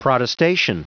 Prononciation du mot protestation en anglais (fichier audio)
Prononciation du mot : protestation